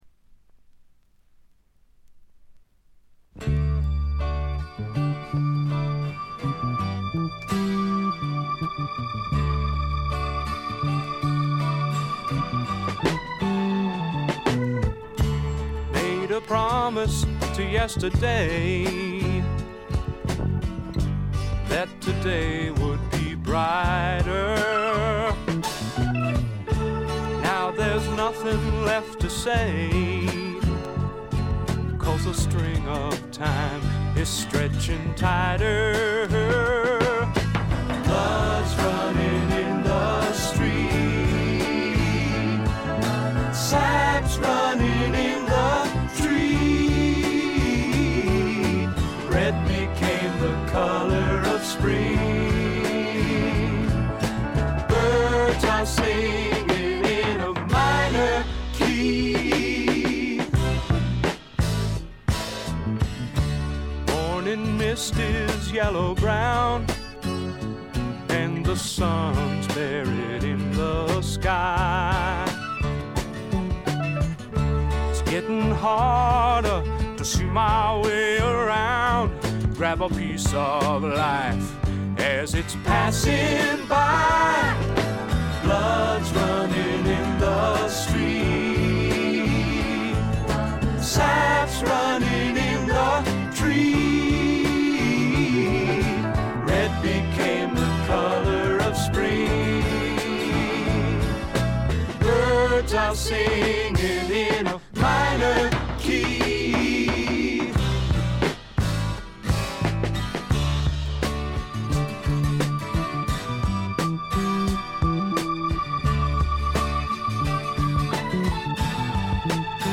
ホーム > レコード：英国 SSW / フォークロック
軽微なチリプチ少々。
静と動の対比も見事でフォークロック好きにとってはこたえられない作品に仕上がっています！
試聴曲は現品からの取り込み音源です。